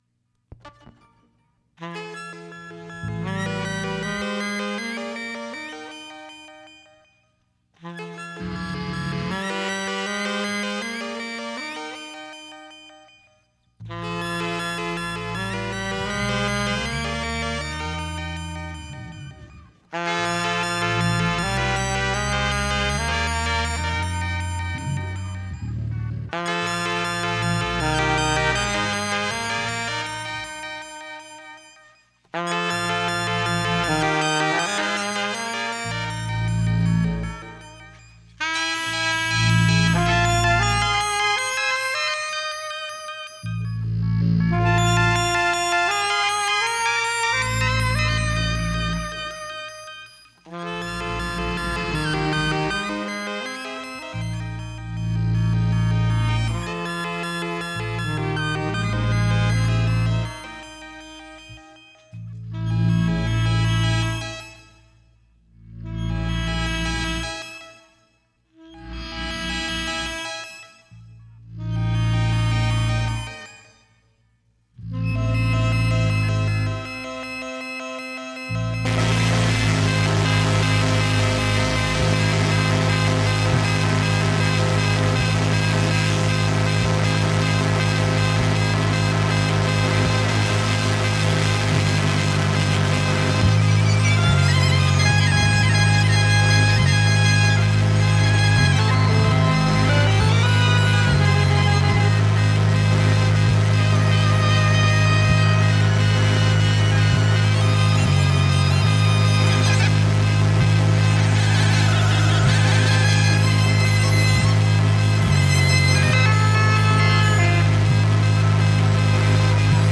Simulated Stereo (722 / Pro Tools) Mosquito
alto sax
electric bass